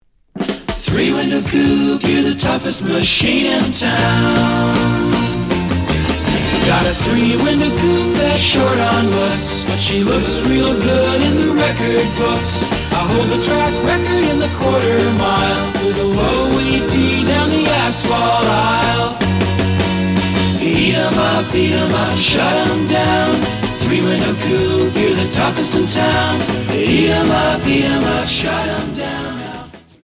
試聴は実際のレコードから録音しています。
●Genre: Rock / Pop